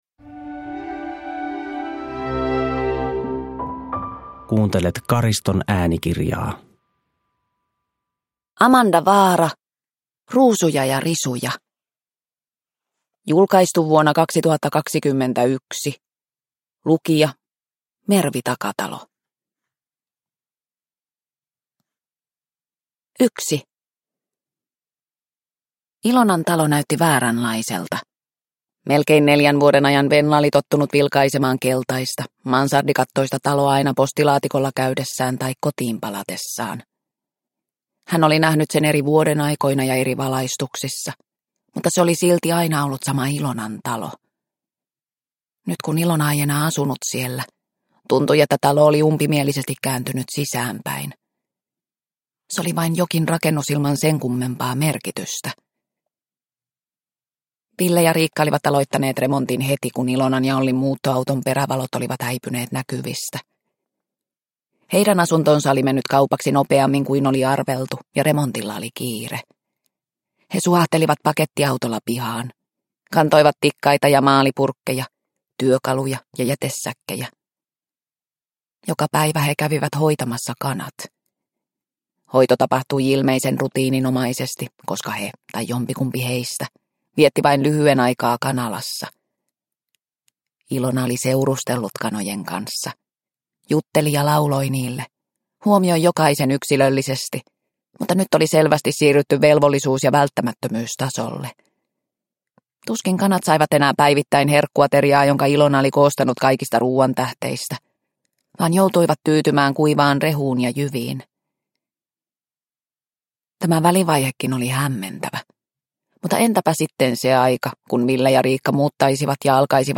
Ruusuja ja risuja – Ljudbok – Laddas ner